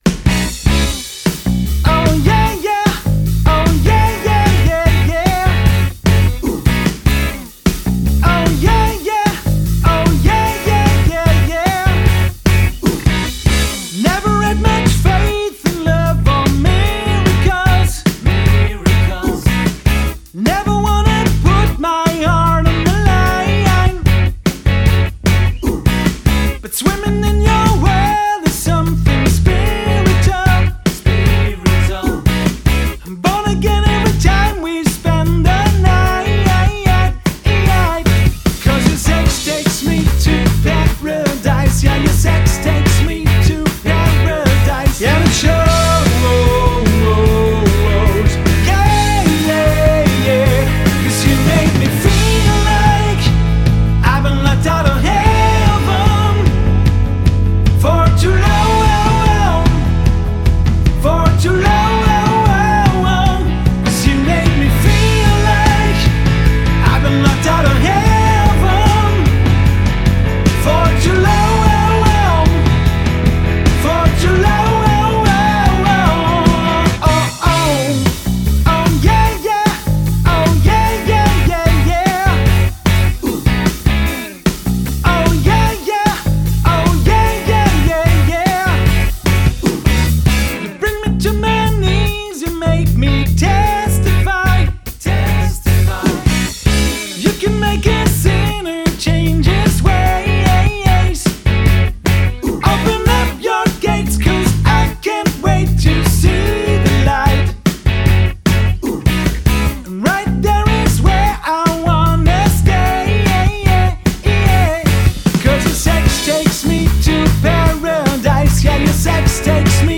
Trio
Rock & Funk mit Stil und noch mehr Energie.